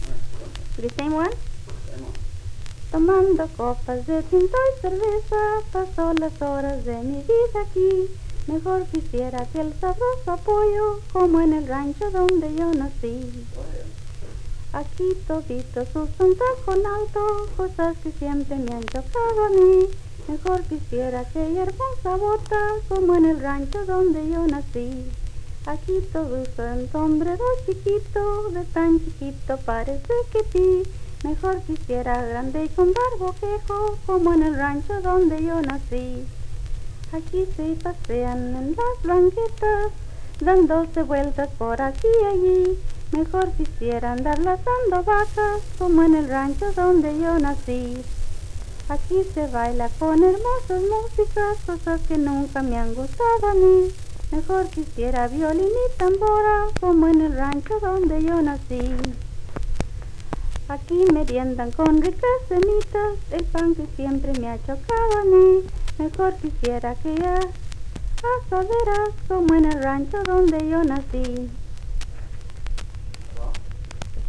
Southern Mosaic: The John and Ruby Lomax 1939 Southern States Recording Trip, Library of Congress.